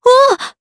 Leo-Vox_Damage_jp_02.wav